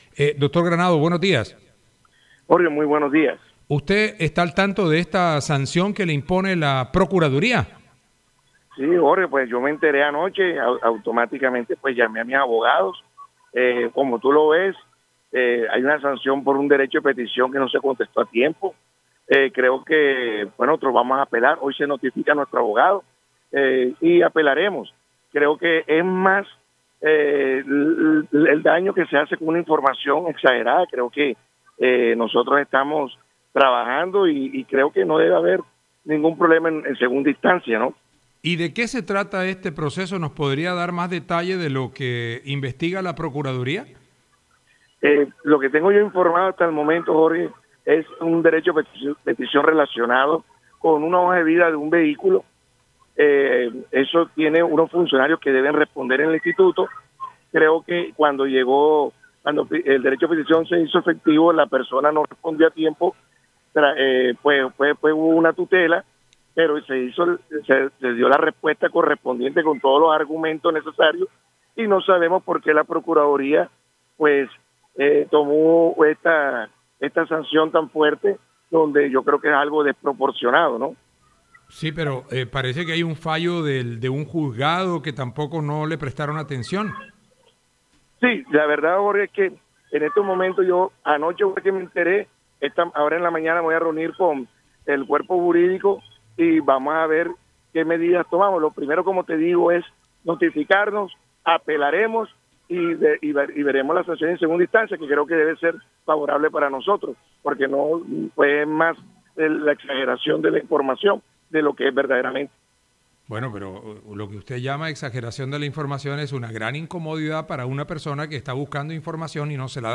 En diálogo con Atlántico en Noticias, el director del Instituto Departamental de Tránsito, Carlos Granados, anunció que apelará la suspensión de dos meses que en primera instancia la impuso la Procuraduría.